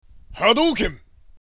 hado-ken.mp3